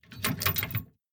combo_locked.ogg